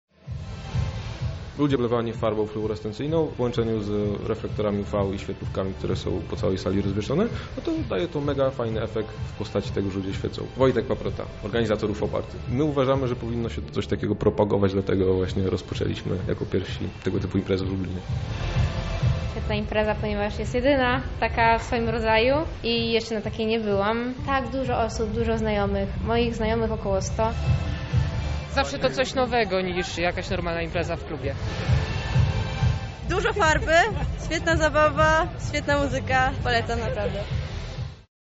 w UV party. Organizatorzy w rytmach klubowych brzmień pokolorowali setki osób i oświetlili